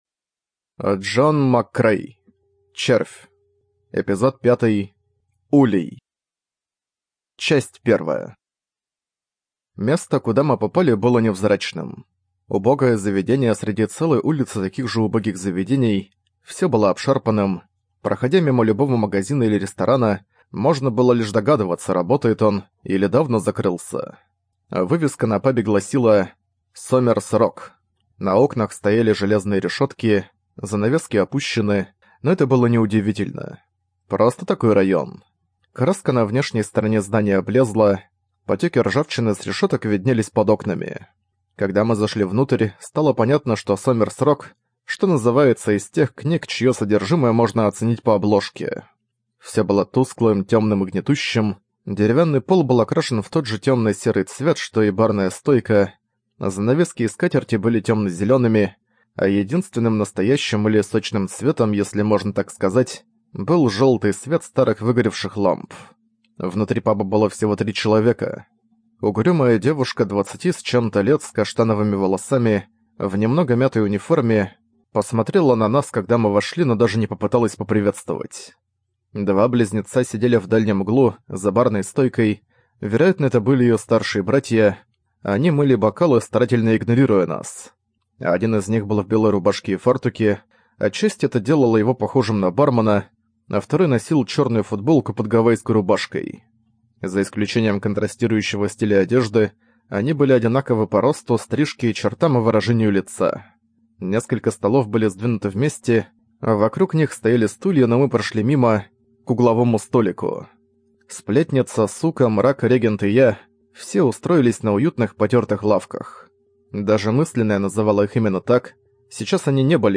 ЖанрФантастика